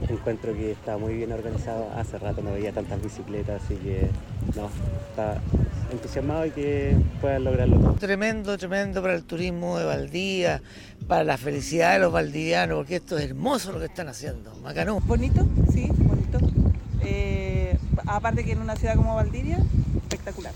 Así lo manifestaron algunos asistentes, quienes tras circular por la costanera, las cercanías del Parque Saval y otros puntos de Valdivia, entregaron sus impresiones a La Radio.